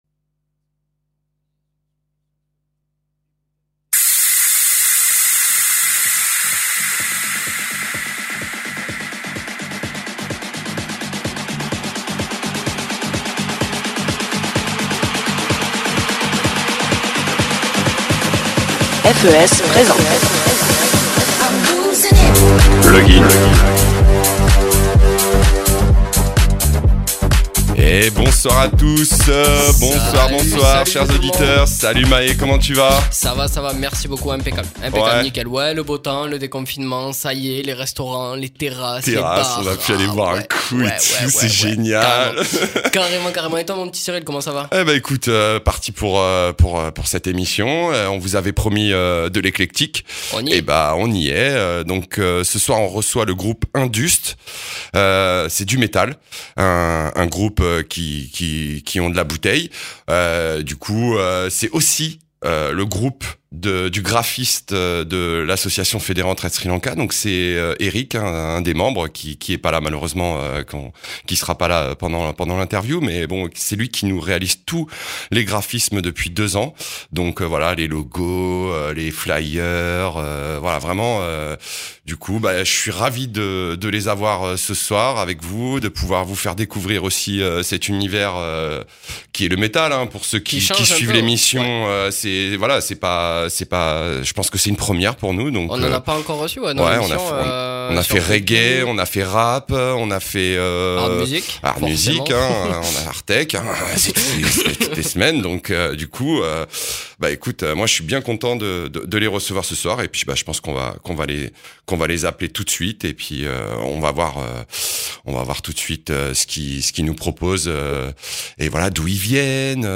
Bienvenue dans l’univers métal/hardcore de ce groupe qui officie sur les scènes de France depuis plus de 25 ans. Accrochez-vous, ça envoie !!!